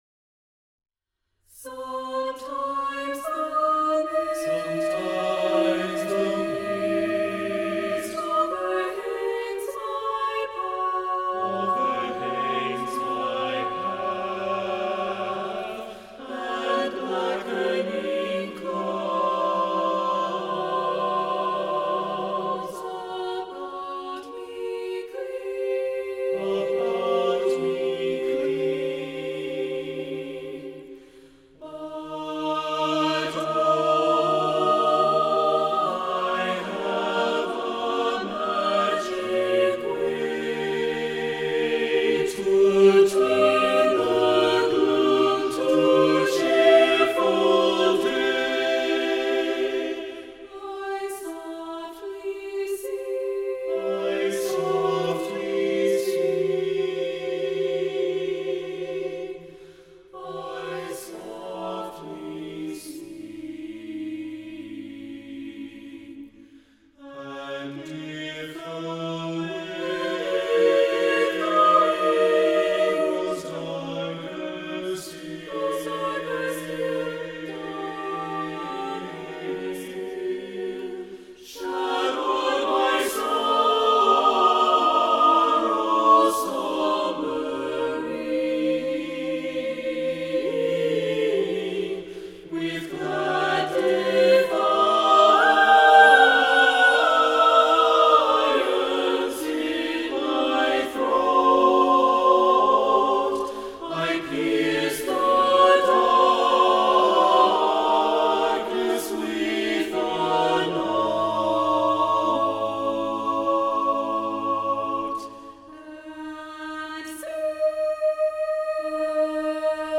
English Choral Compositions
SATB a cappella